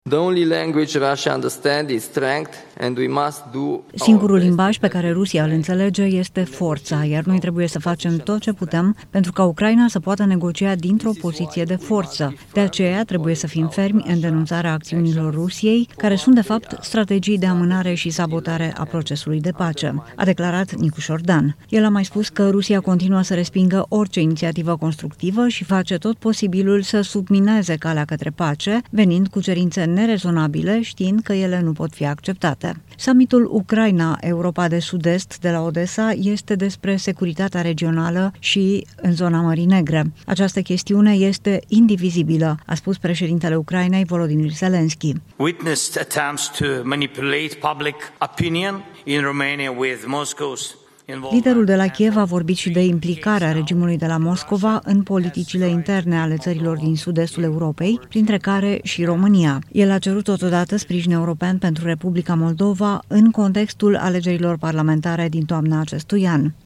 Preşedintele Nicuşor Dan a cerut cu fermitate denunţarea acţiunilor Rusiei, care – spune șeful statului – foloseşte „strategii de amânare şi sabotare a procesului de pace”. Declarațiile au fost făcute miercuri seară, 11 iunie, la Summitul „Ucraina – Europa de Sud-Est”.